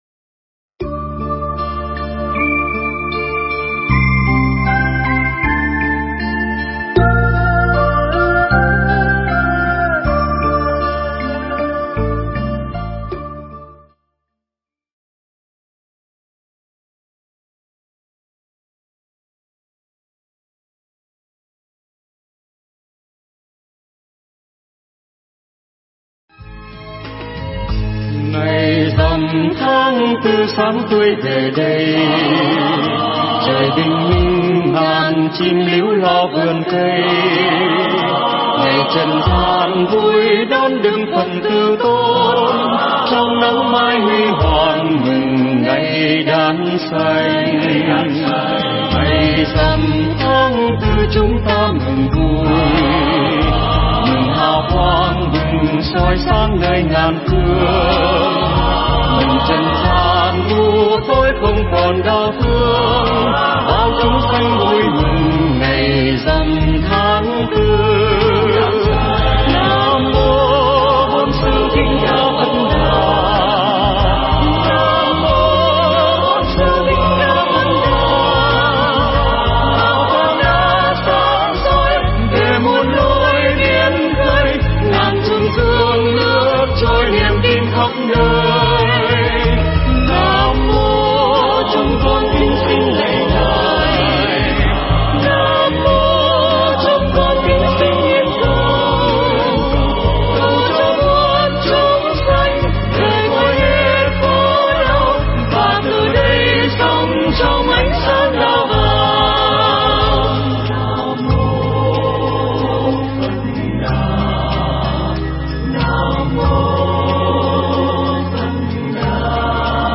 Nghe Mp3 thuyết pháp Gương Xưa
Mp3 pháp thoại Gương Xưa